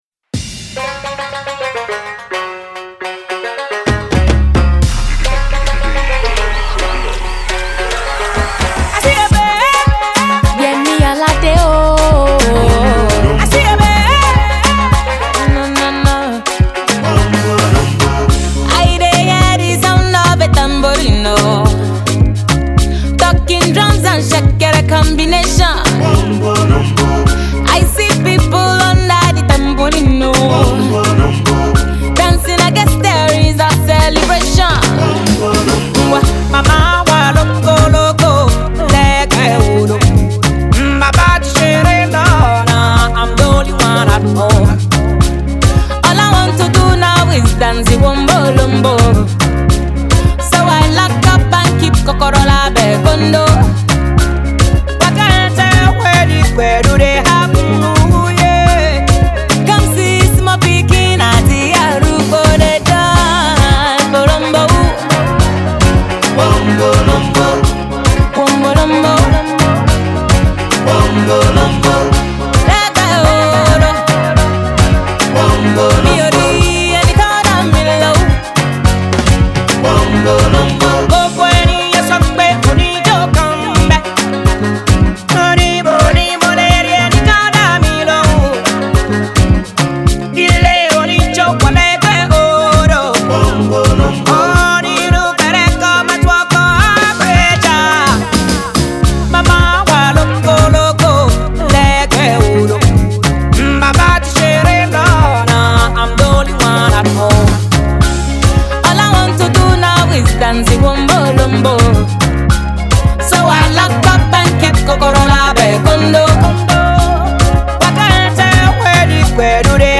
a modern remake